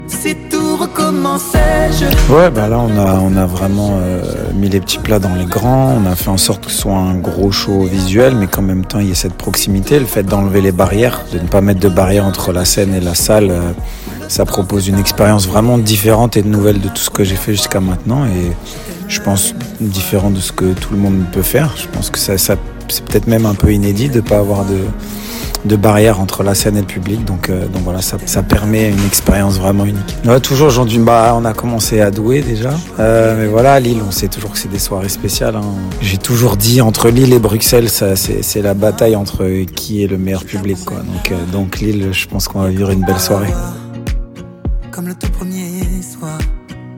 L’ ADRENALINE TOUR , la nouvelle tournée de MATT POKORA faisait une première halte au zénith de Lille Samedi dernier avant un second round Dimanche 30 Novembre .
POKORA-LILLE-NOV-25.mp3